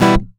OVATION E-.2.wav